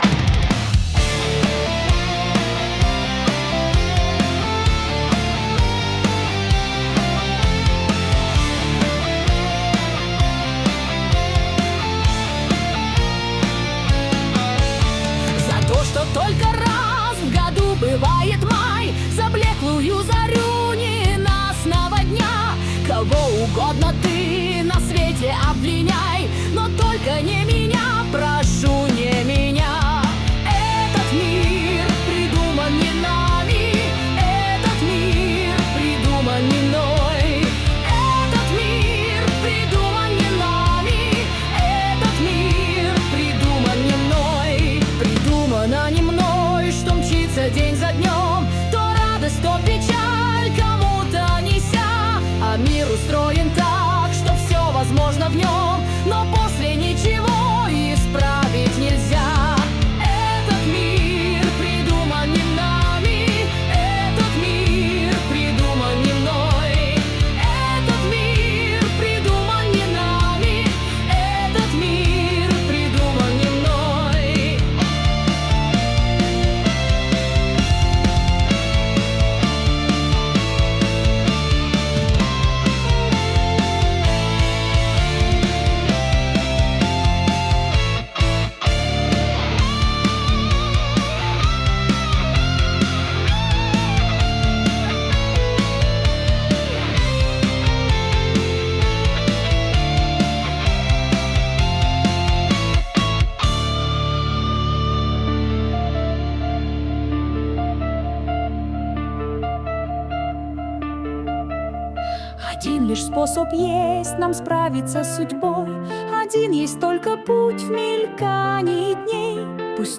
В Hard Rock Стиле Cover